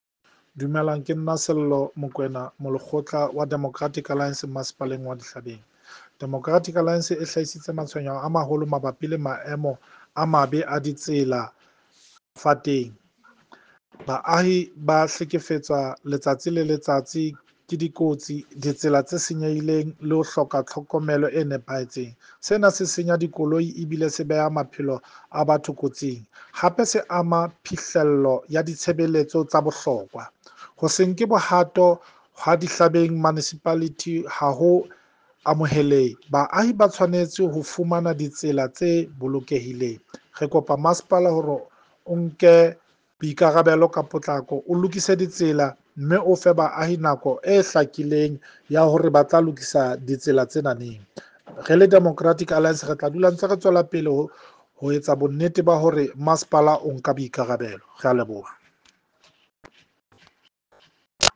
Sesotho soundbites by Cllr Sello Makoena and